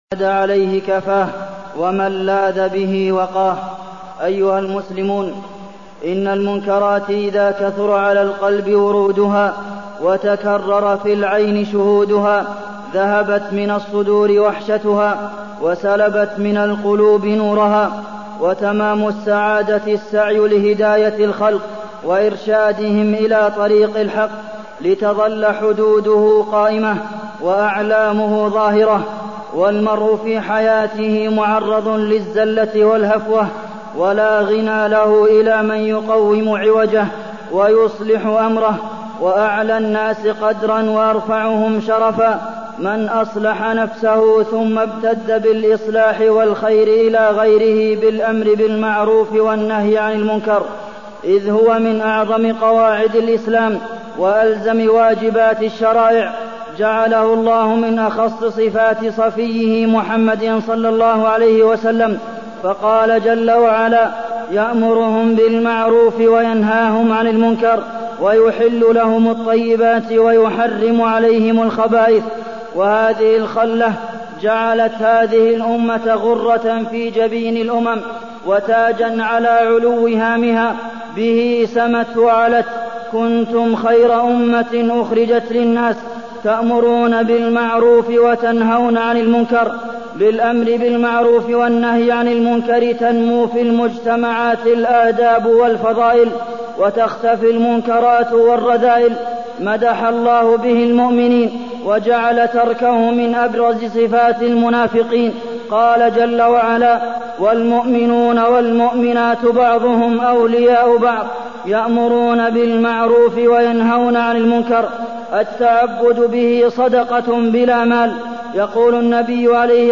تاريخ النشر ٤ شعبان ١٤٢٠ هـ المكان: المسجد النبوي الشيخ: فضيلة الشيخ د. عبدالمحسن بن محمد القاسم فضيلة الشيخ د. عبدالمحسن بن محمد القاسم الأمر بالمعروف والنهي عن المنكر